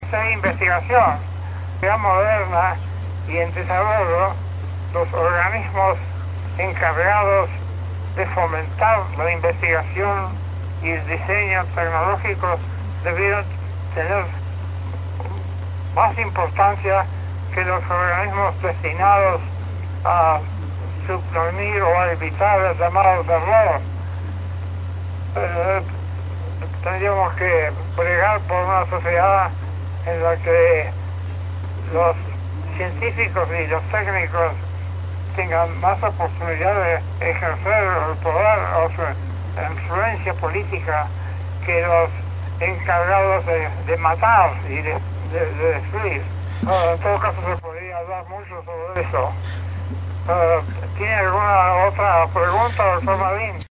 Entrevista reducida al Dr. Mario Bunge
dcterms.abstractEste audio incorporado al Repositorio Digital de la Comisión de Investigaciones Científicas es producto de una entrevista telefónica realizada al reconocido filósofo de la ciencia Dr. Mario Bunge, residente en Canadá. Tal reportaje se grabó el 27 de agosto de 2016 en los estudios de FM Platense (89.9),y un extracto de la misma fue presentada durante el cierre del III Congreso Internacional de Ciencia y Tecnología organizado por la CIC en septiembre de 2016 en la ciudad de La Plata.